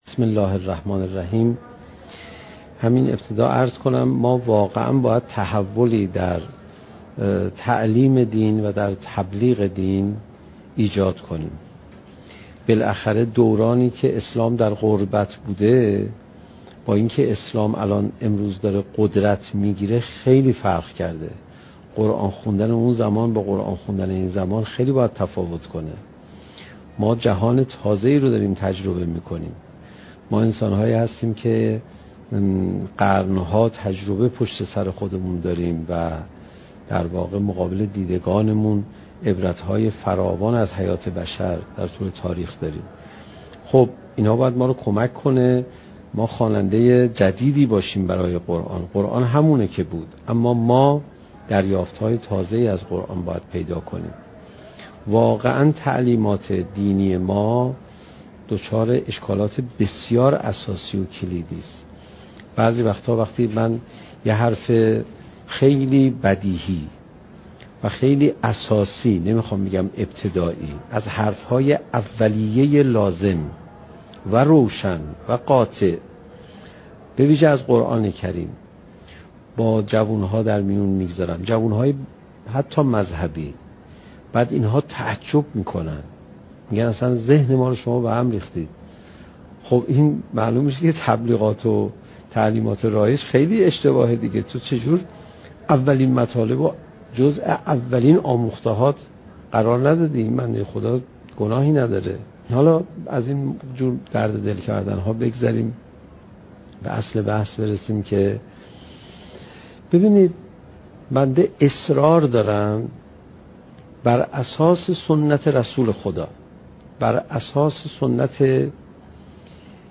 سخنرانی حجت الاسلام علیرضا پناهیان با موضوع "چگونه بهتر قرآن بخوانیم؟"؛ جلسه بیست و پنجم: "جلوه اشک در قرائت قرآن"